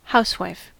Ääntäminen
Tuntematon aksentti: IPA: /ˈhaʊ̯sfʀaʊ/